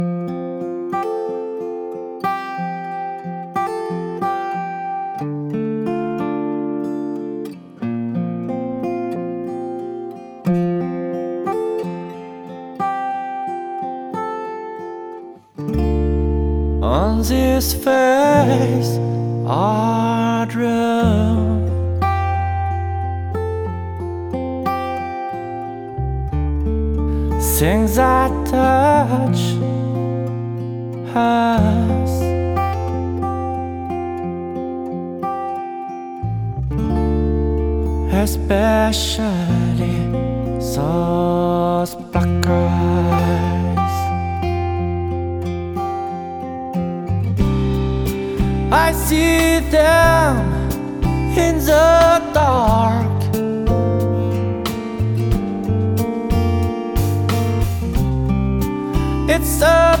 Entre folk intimiste, accents soul et éclats rock
Guitariste passionné